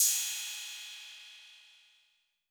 Southside Open Hatz (8).wav